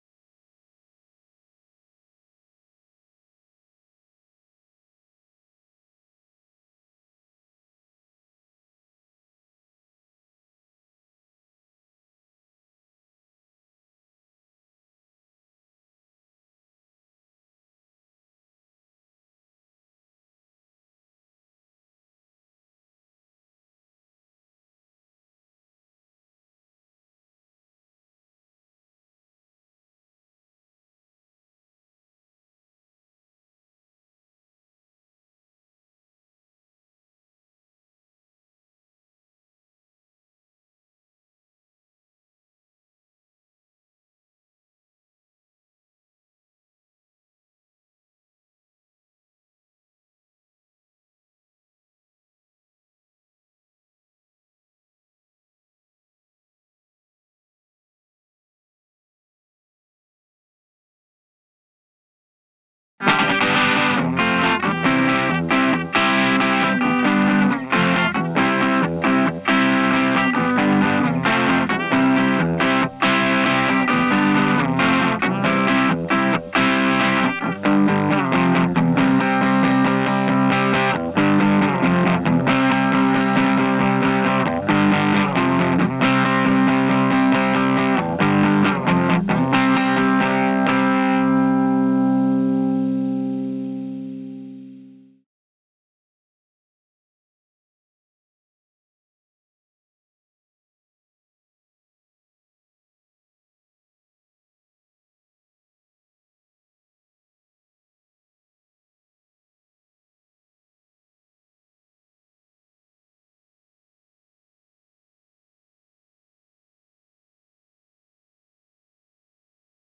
Faith - Reaper Remix_freeze_Faith_Crunch Guitar 2.wav